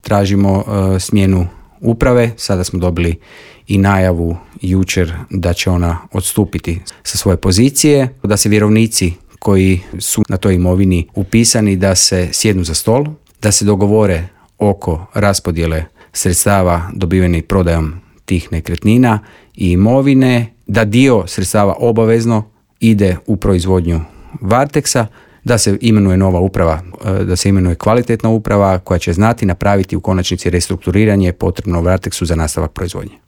Za trenutno stanje u Varteksu nisu krivi radnici, već loše upravljanje, poručio je u Intervjuu Media servisa